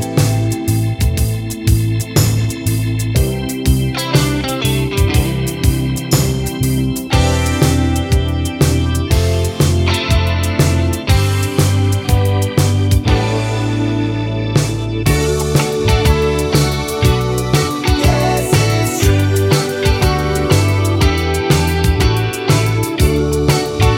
No Organ Solo Pop (1980s) 4:29 Buy £1.50